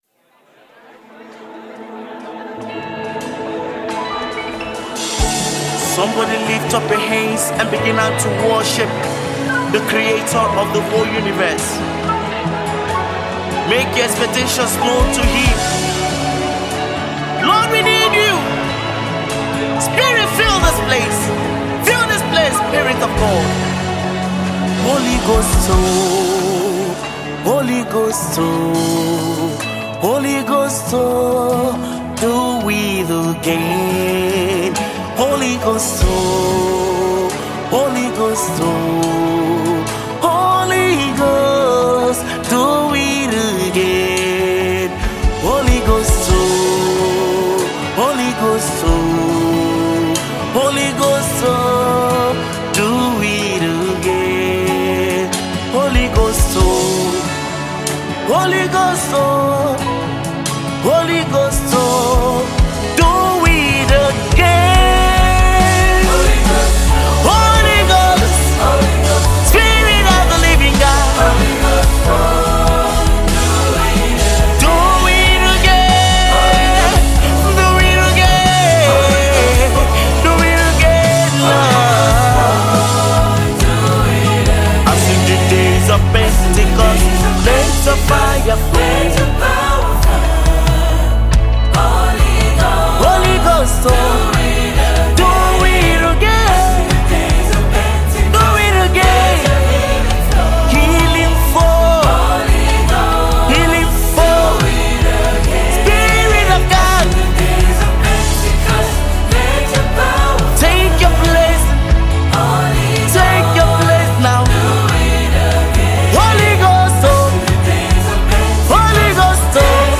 spirit-filled worship anthem
With powerful vocals and heartfelt lyrics